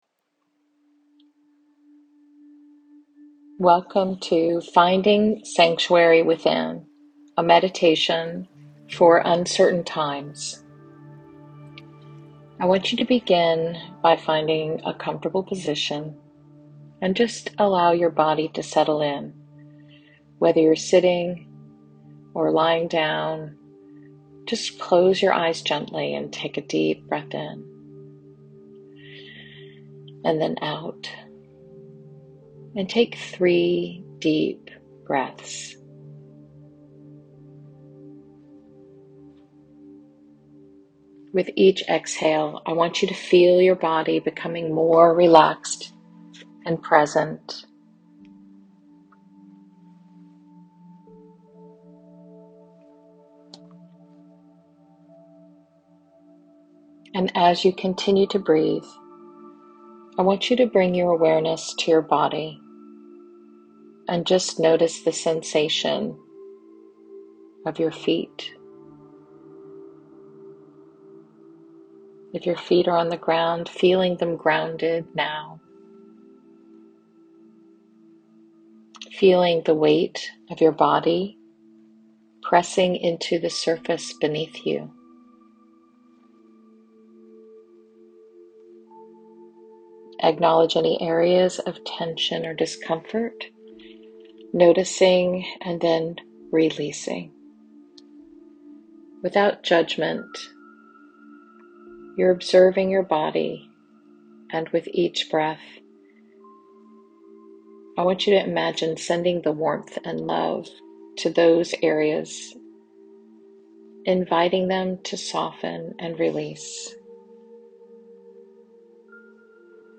Meditation of the Week: Grounding in the Unknown This meditation helps establish a sense of stability even when everything feels uncertain.